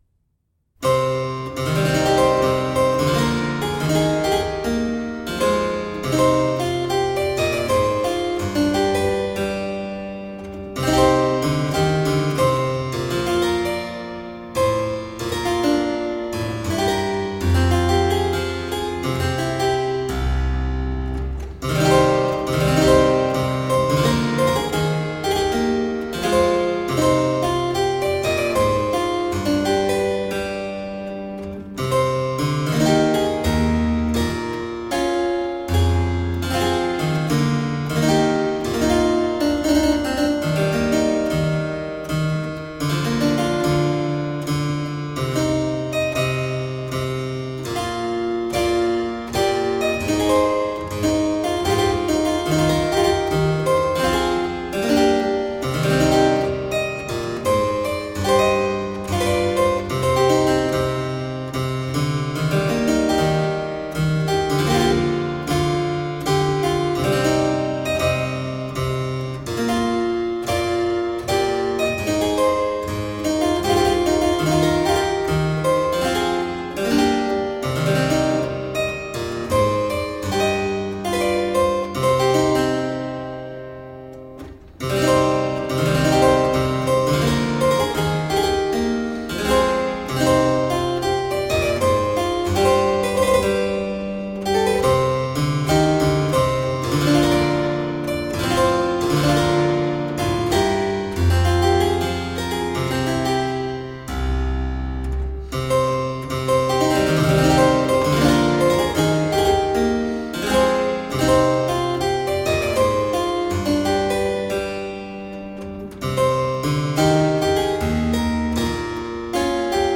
Vibrant harpsichord-music.
Classical, Baroque, Instrumental
Harpsichord